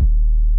tm 808_2.wav